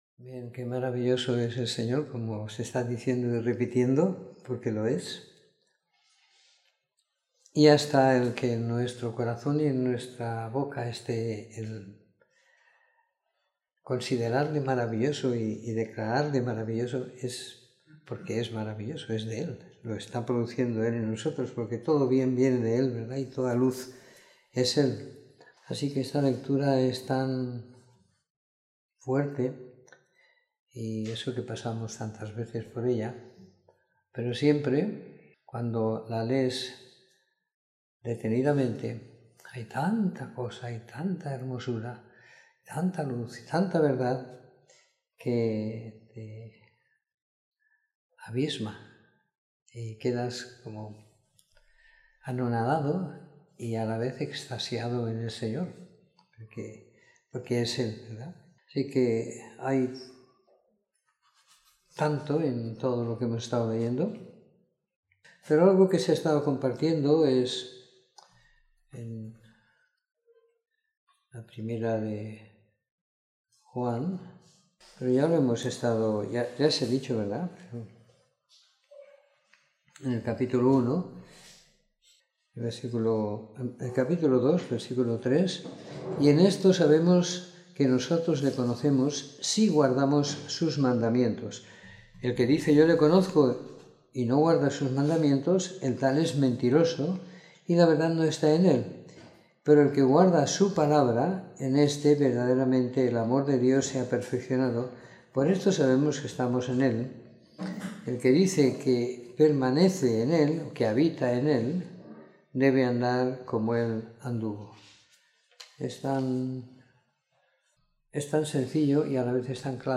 Comentario en los libros de 1ª de Juan a Judas y en el Evangelio de Juan del capítulo 11 al 21 siguiendo la lectura programada para cada semana del año que tenemos en la congregación en Sant Pere de Ribes.